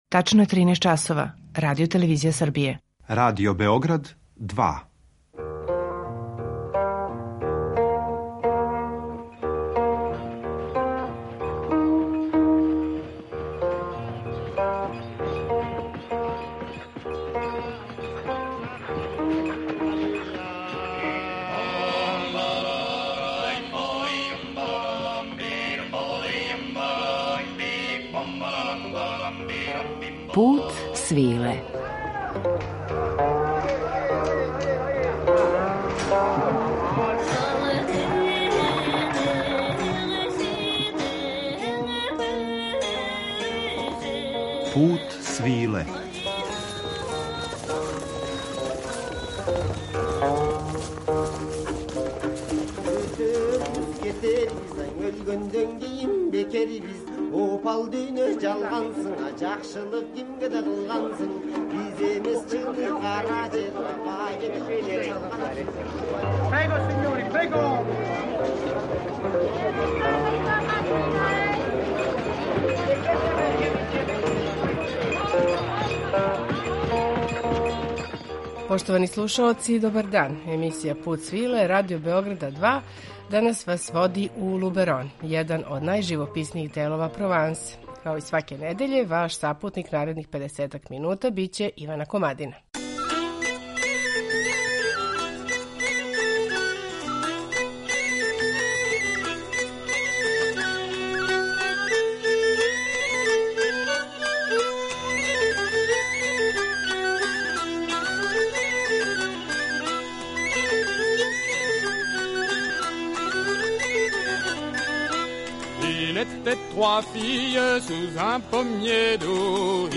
Пут свиле, као јединствено “радијско путовање”, недељом одводи слушаоце у неку од земаља повезаних са традиционалним Путем свиле, уз актуелна остварења из жанра “World music” и раритетне записе традиционалне музике.
У живописни крајолик Либерона, Великог и Малог, уронићемо у данашњем Путу свиле , у друштву групе „La Bamboche", која негује музичку традицију континенталних делова Француске.